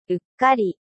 うっかり [3]